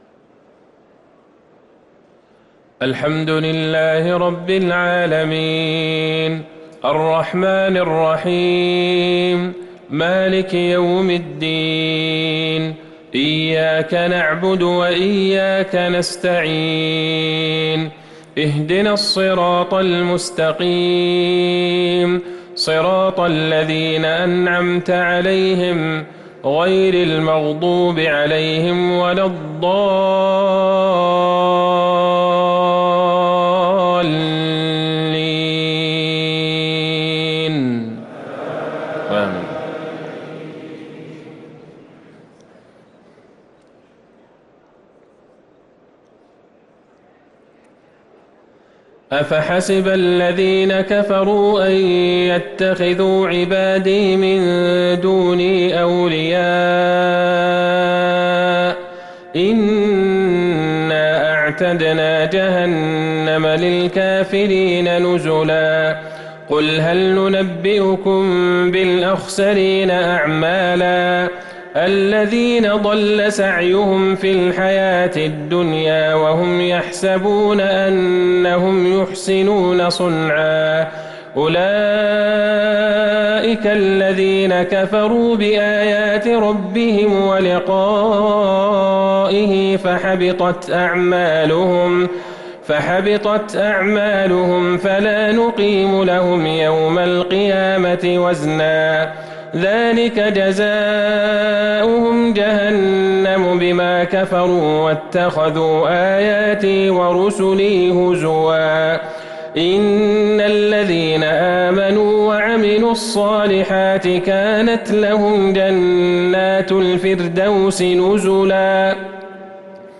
صلاة العشاء للقارئ عبدالله البعيجان 24 جمادي الأول 1444 هـ